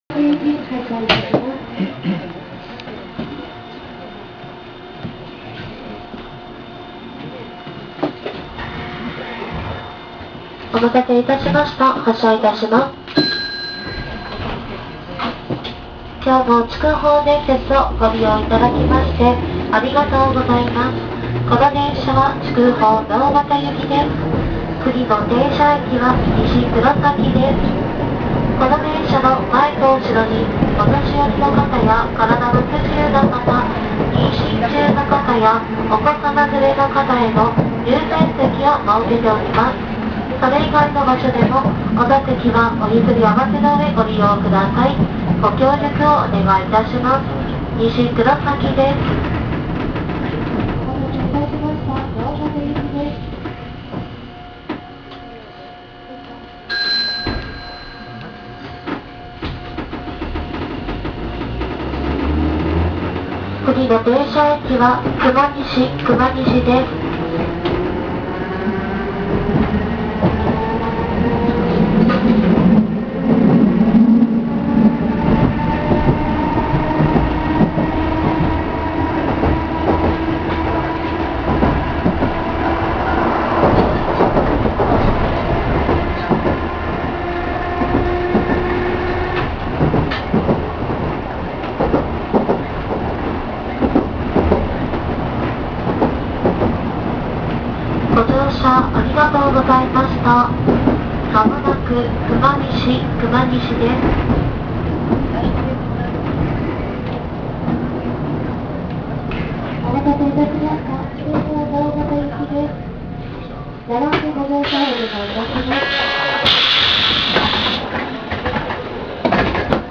・3000形走行音
【筑豊電鉄線】黒崎駅前〜熊西（2分1秒：655KB）
2000形の機器流用で登場した車両なので、それなりに新しく見えますがこれでも吊り掛け式。路面電車型の車両ではありますが、路線自体は普通鉄道である為、割と速度を出して派手な走行音を出してくれます。かつては自動放送が無く車掌の肉声放送のみだったようです（黒崎駅前〜熊西の走行音が該当）が、現在は自動放送が導入されています。